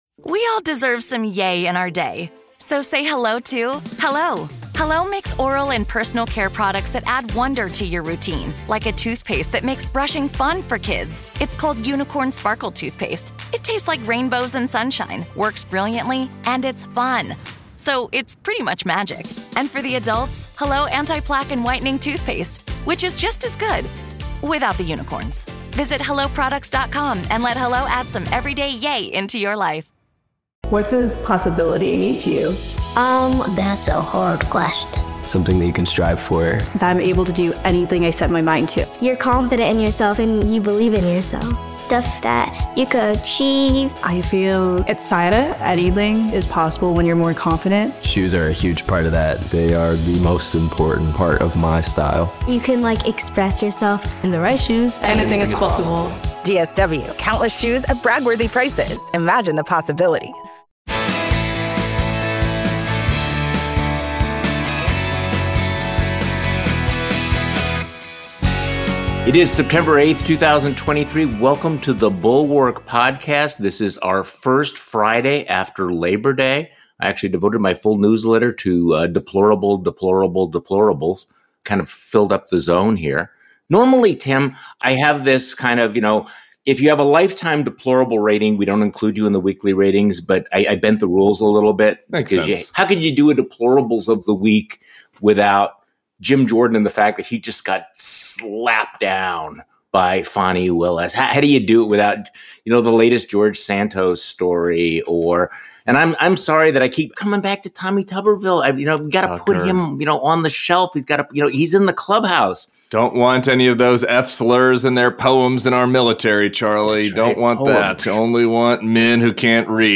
Plus, Santos' oppo on himself, and the potential Saudi deal. Tim Miller joins Charlie Sykes for the weekend pod.